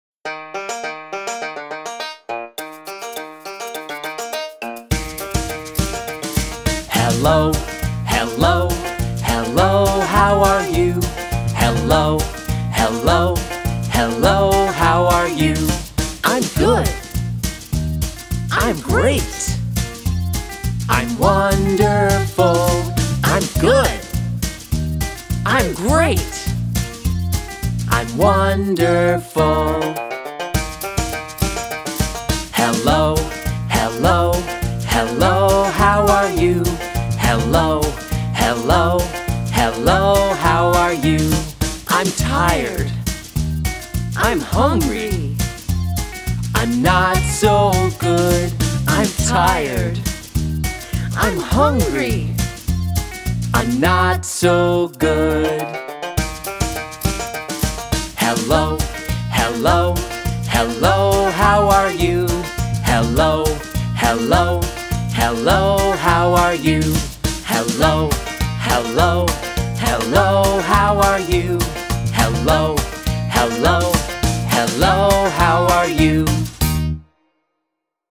NA POWITANIE ZAŚPIEWAJMY PIOSENKĘ